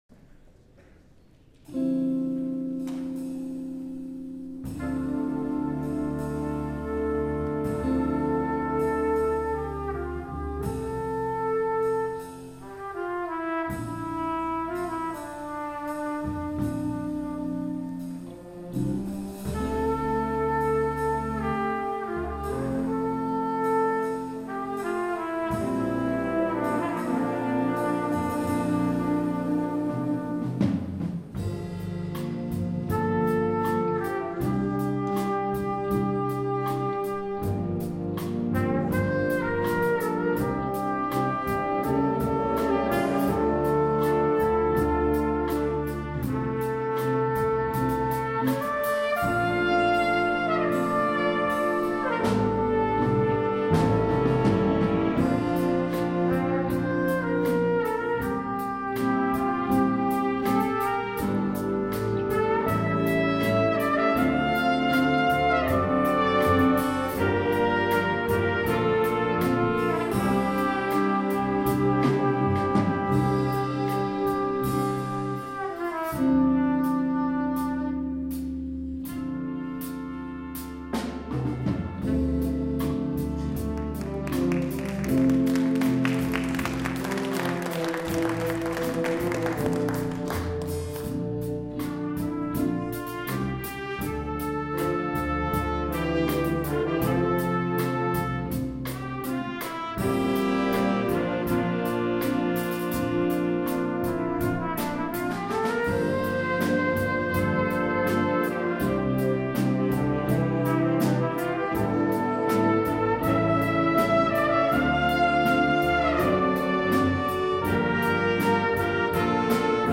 2017 Jazz Festival